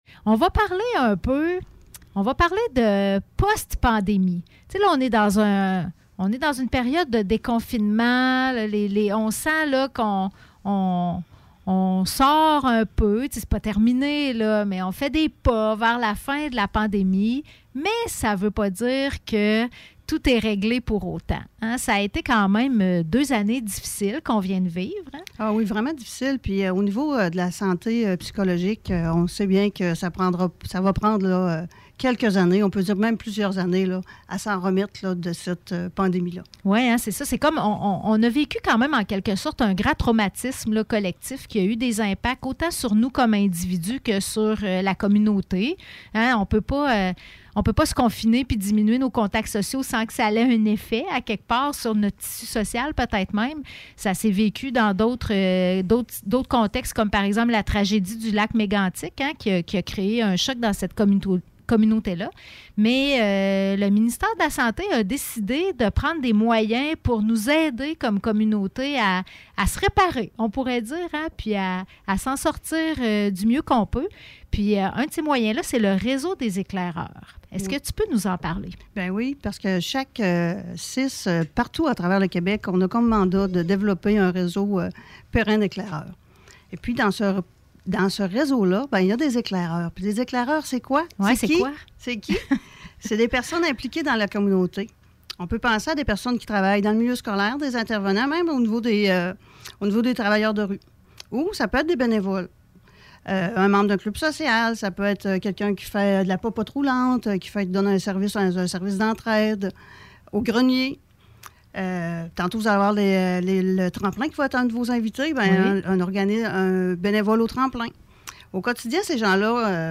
Entrevue réalisée à la radio CJMD 96,9